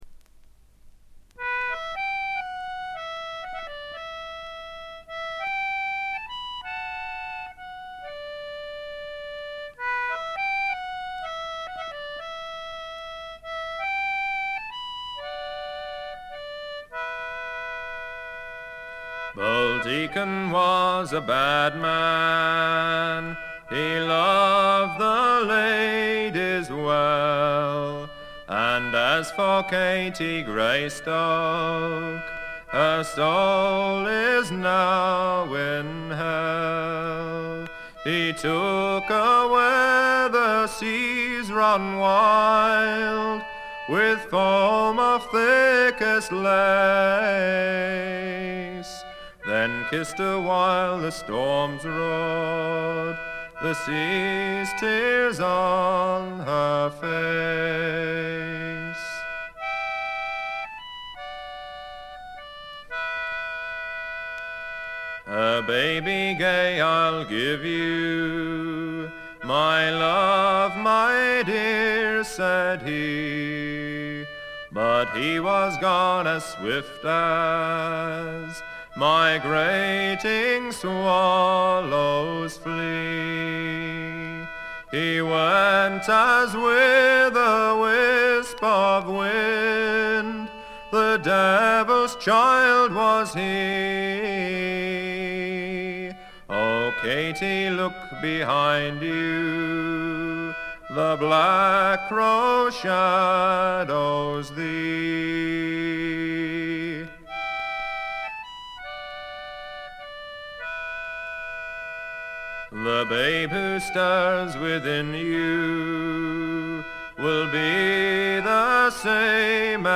軽微なバックグラウンドノイズのみ。
試聴曲は現品からの取り込み音源です。